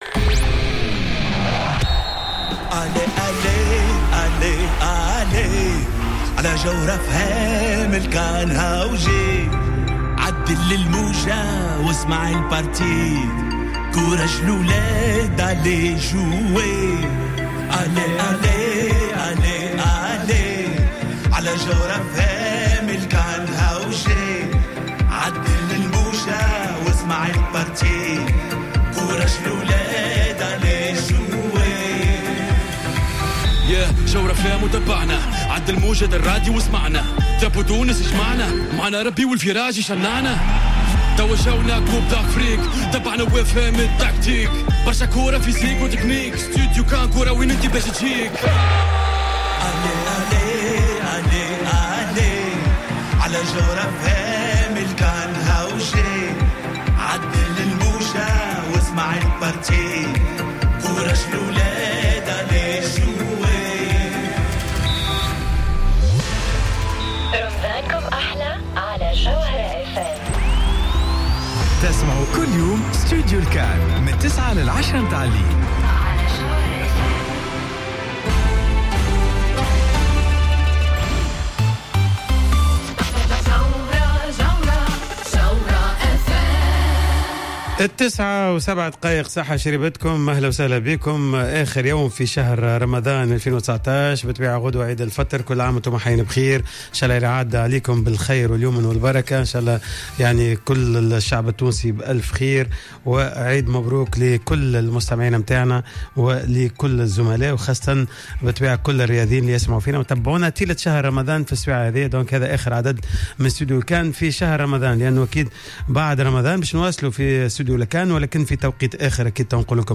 خلال تدخله عبر الهاتف مباشرة من باريس